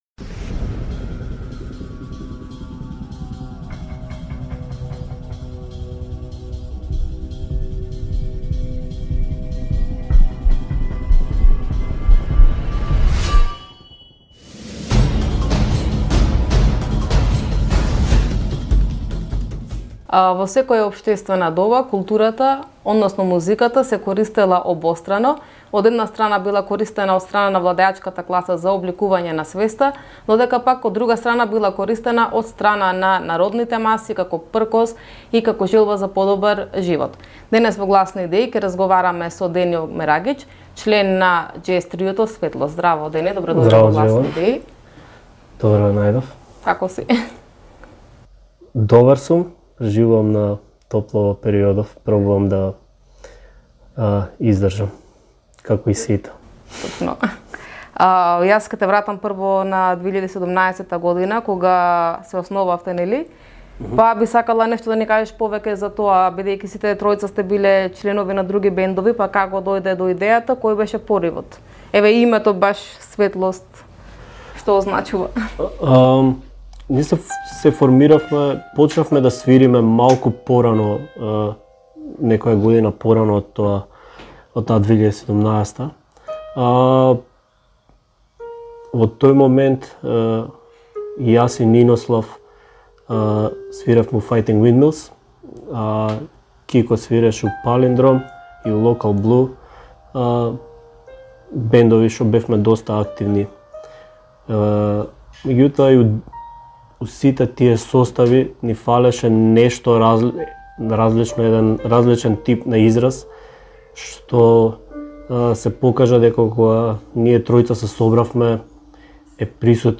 ИНТЕРВЈУ: Музиката и општествените промени / INTERVISTË: Muzika dhe ndryshimet shoqërore